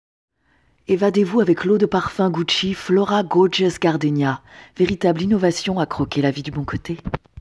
Voix off
Autonome pour enregistrer, chant ou voix, je possède du materiel professionnel (Neumann et Sennheiser)